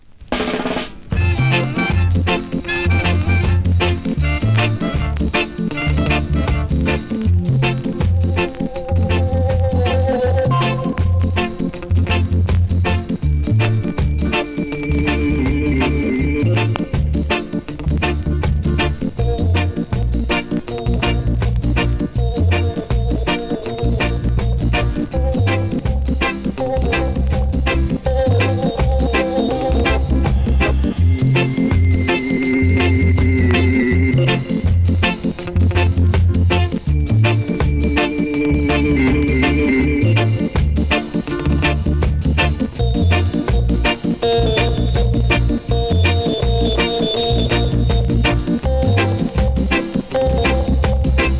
スリキズ、ノイズ比較的少なめで